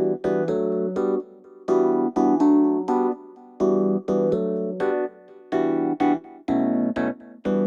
32 ElPiano PT2.wav